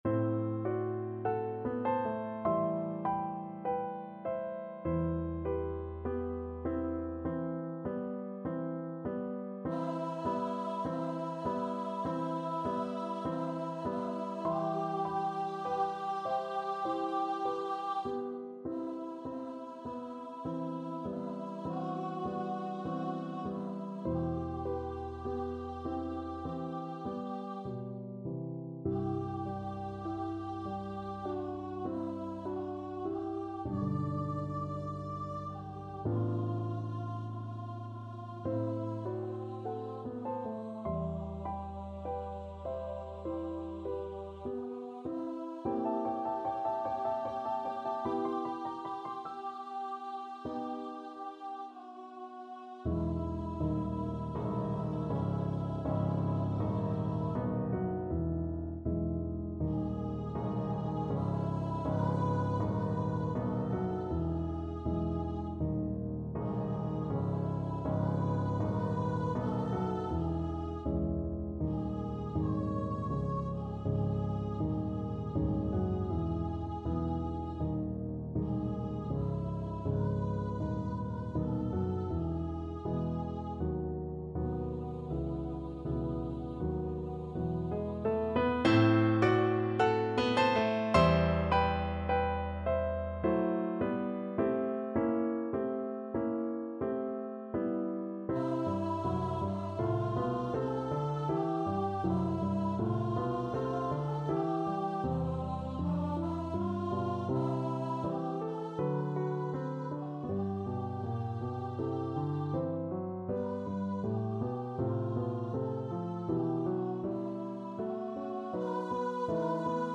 Free Sheet music for Voice
C major (Sounding Pitch) (View more C major Music for Voice )
4/4 (View more 4/4 Music)
Andantino (=50) (View more music marked Andantino)
Voice  (View more Intermediate Voice Music)
Classical (View more Classical Voice Music)